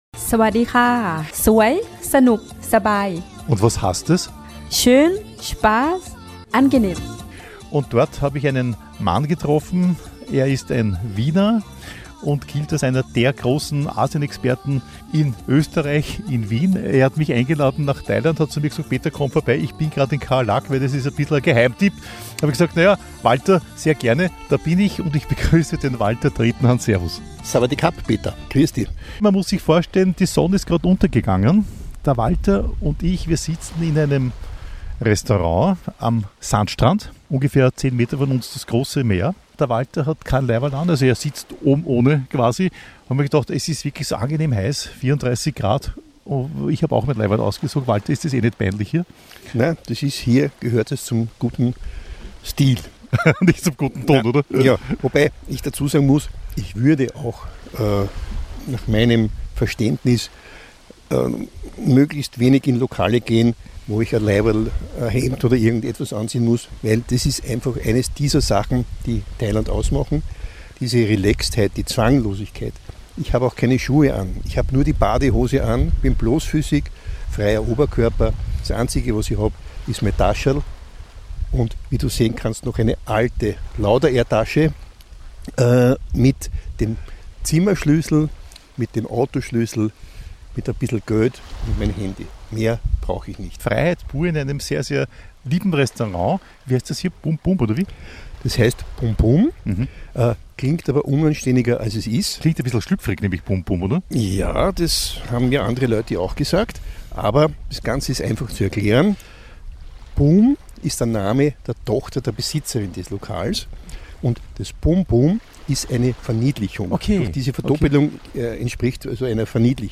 Treffpunkt war ein Strand-Restaurant im Bangsak Beach.